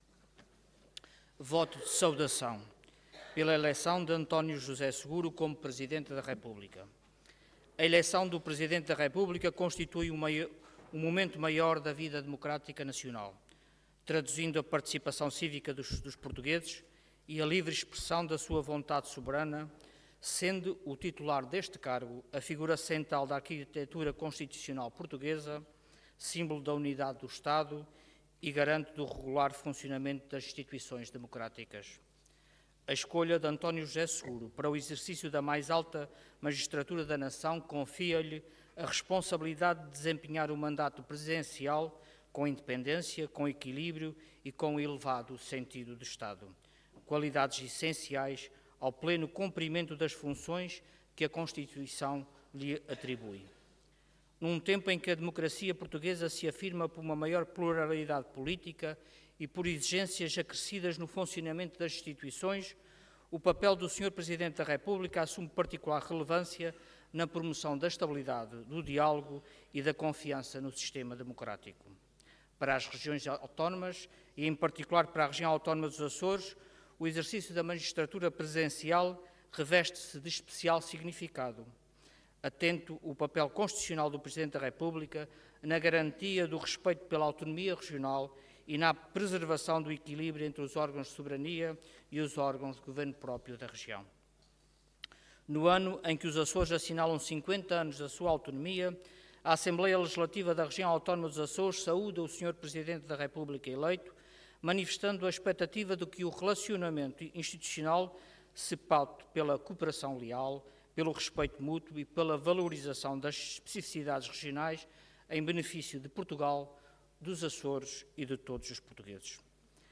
Em destaque 25 de fevereiro de 2026 Download áudio Download vídeo XIII Legislatura Voto de Saudação pela eleição de António José Seguro como Presidente da República Intervenção Voto de Saudação Orador Luís Garcia Cargo Presidente da Assembleia Regional Entidade ALRAA